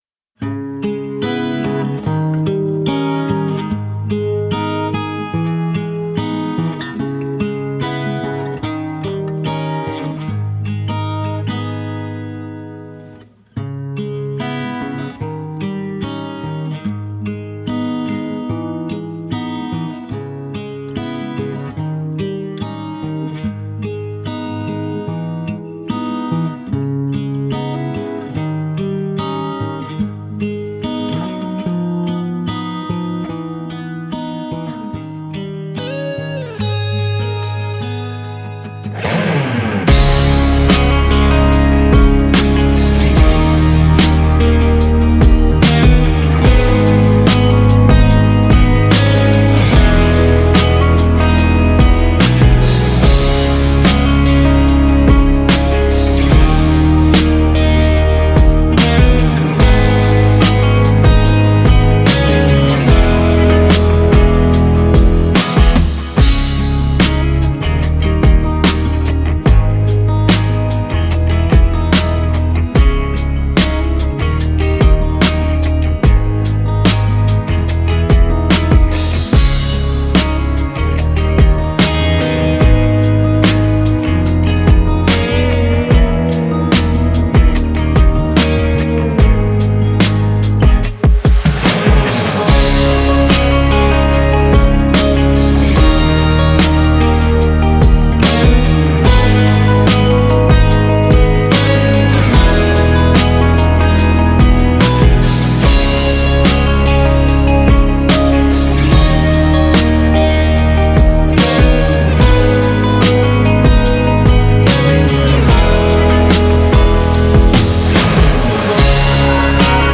• Жанр: Instrumental